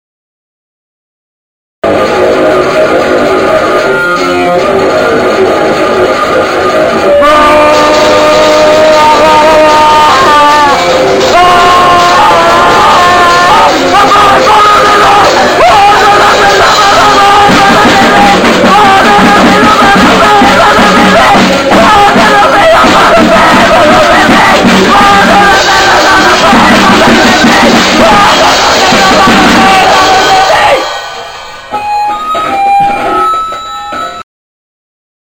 penn state's premiere grindcore band.